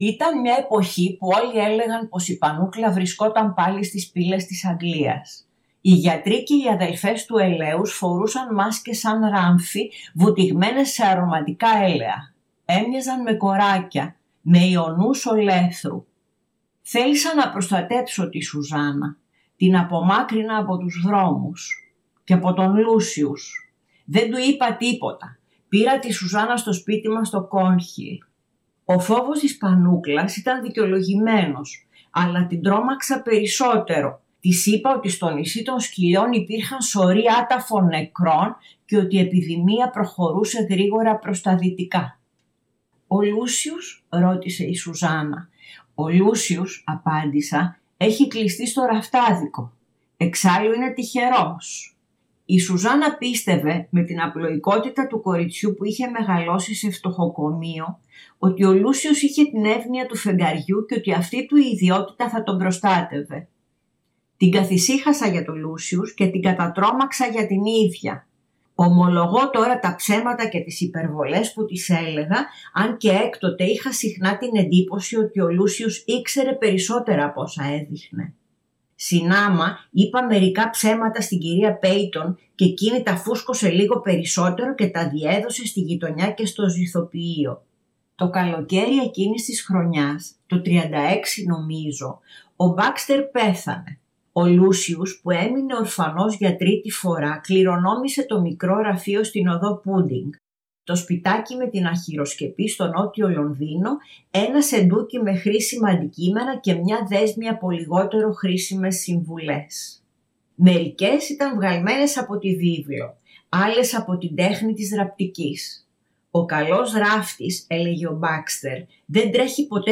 Διαβάζει απόσπασμα από το βιβλίο της «Το τέλος του κόσμου σε Αγγλικό κήπο», εκδ. Πατάκη